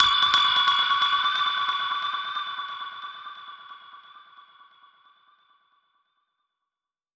Index of /musicradar/dub-percussion-samples/134bpm
DPFX_PercHit_C_134-01.wav